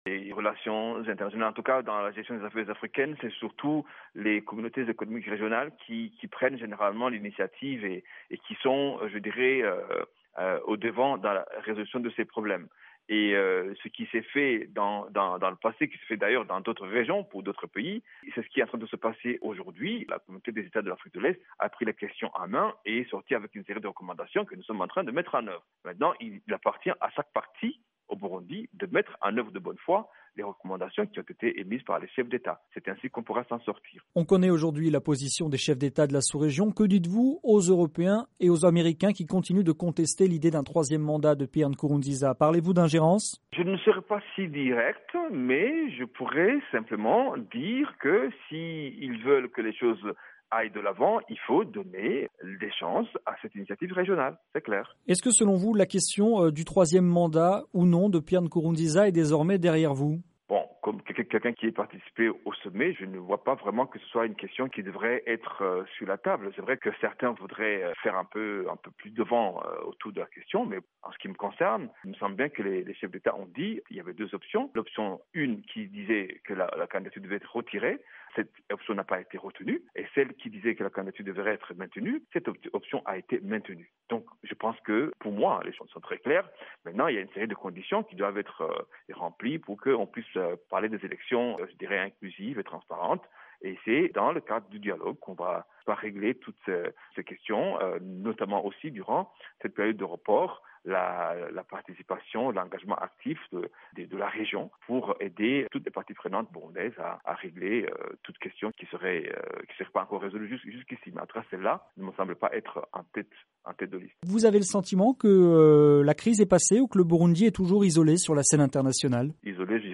Le ministre burundais des affaires étrangères a déclaré dans une interview à VOA Afrique que l’heure du bilan avait sonné pour ceux qui ont appelé aux manifestations au Burundi.